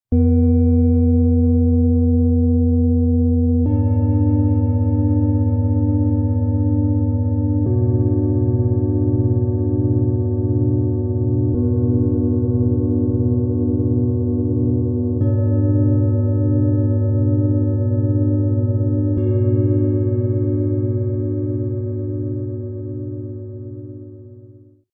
Großes Klangmassage-Set: Tiefe, Harmonie und Ruhe
Ein Set aus 3 großen, harmonisch abgestimmten Klangschalen mit vollen Vibrationen.
Die größte Schale hat eine tiefe, volle Vibration, die den Bereich von den Füßen bis zum Becken anspricht.
Der mittlere Ton ist fein, harmonisch und sehr freundlich.
Die oberste Schale hat einen klaren und tragenden Ton, der eine ruhige Weite im Kopfbereich schafft.
Mit dem Sound-Player - Jetzt reinhören können Sie den Originalklang gerade dieser Schalen des Sets genau anhören.
MaterialBronze